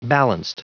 Prononciation du mot balanced en anglais (fichier audio)
Prononciation du mot : balanced